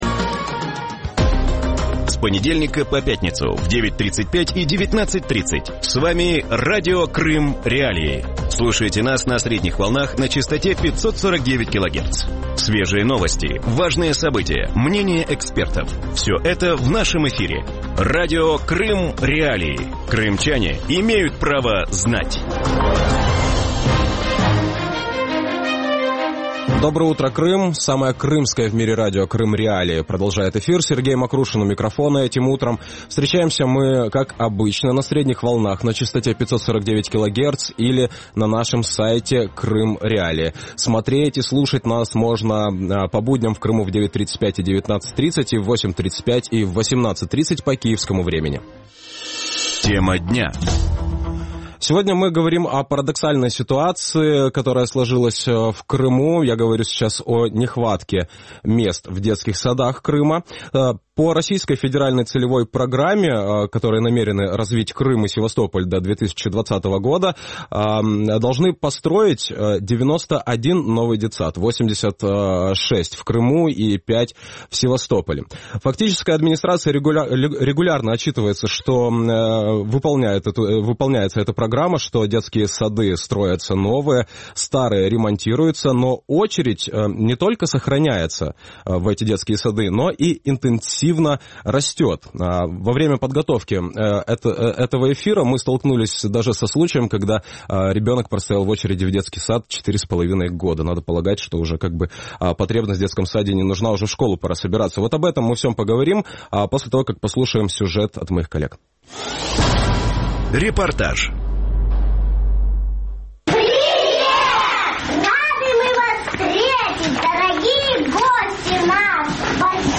В утреннем эфире Радио Крым.Реалии говорят о проблеме нехватки мест в детских садах Крыма.